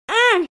issac hurt 1 Meme Sound Effect
issac hurt 1.mp3